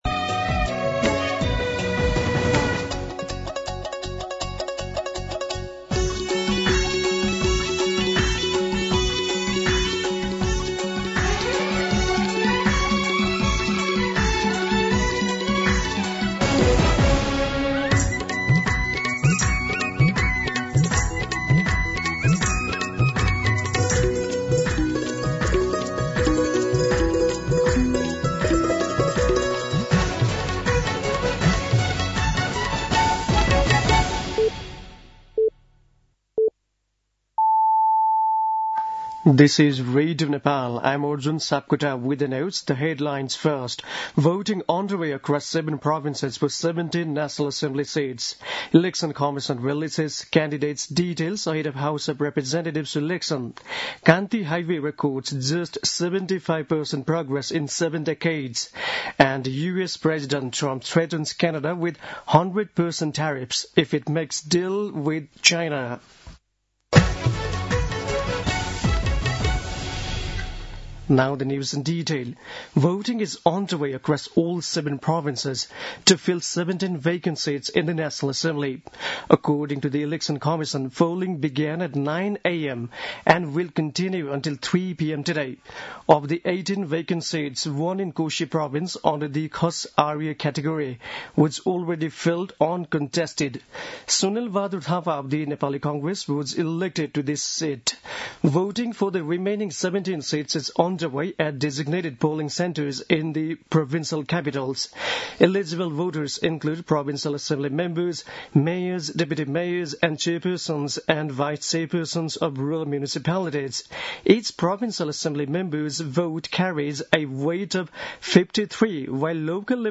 दिउँसो २ बजेको अङ्ग्रेजी समाचार : ११ माघ , २०८२
2-pm-News-10-11.mp3